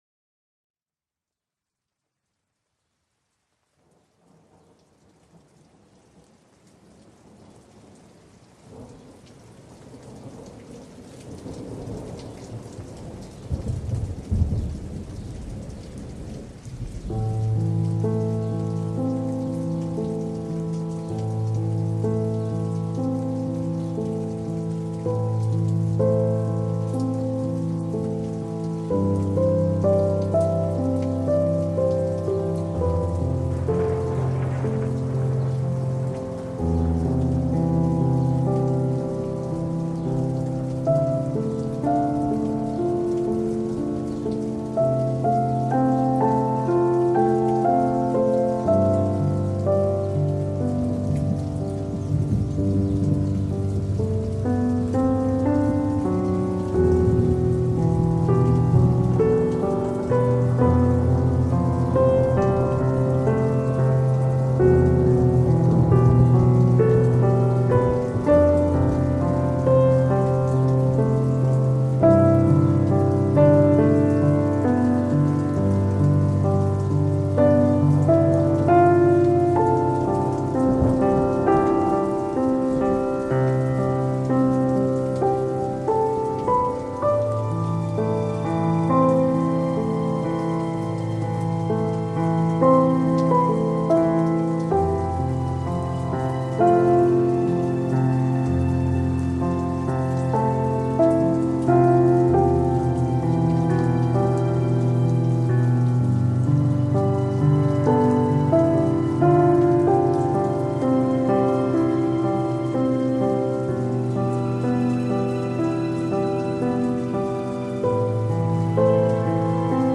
Ce chant vibratoire amplifie les ondes positives
CHANTS VIBRATOIRES
Meditation-amplifie-les-ondes-positives.mp3